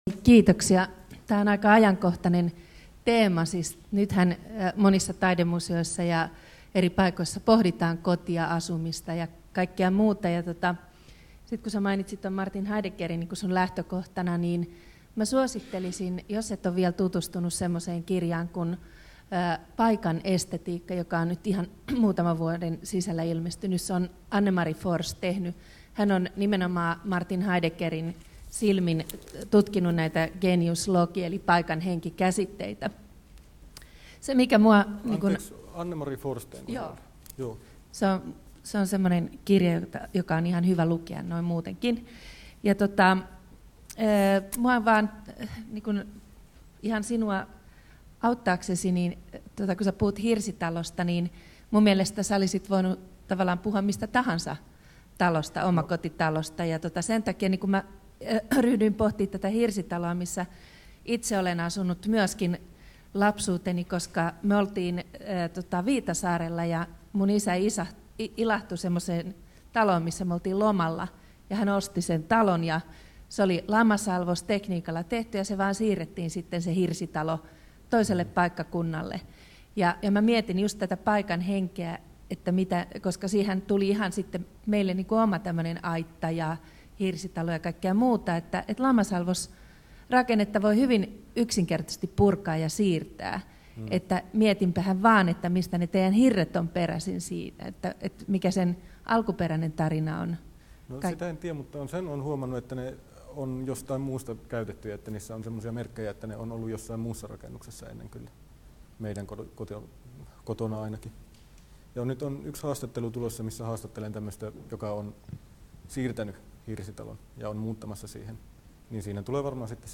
Keskustelu